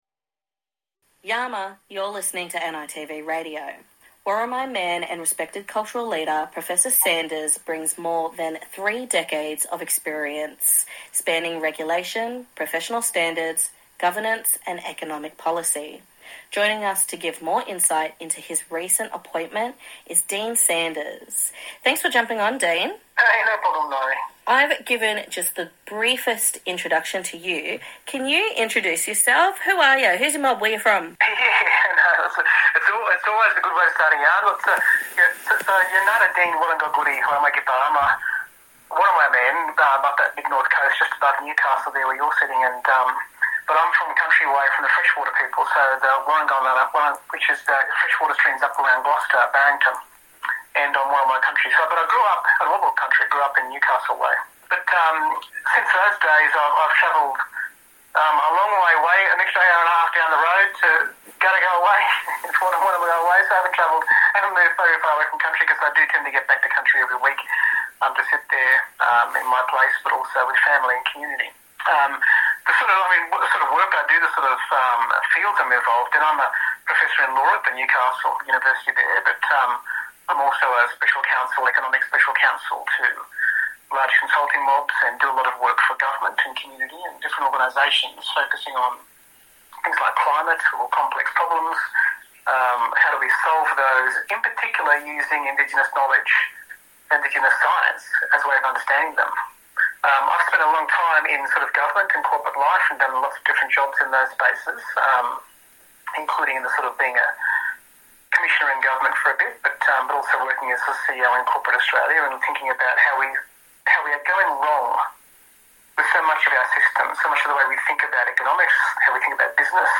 A national focus on news, events & issues that affect Aboriginal and Torres Strait Islander communities. Hear interviews and stories from the SBS NITV Radio program, part of SBS Audio.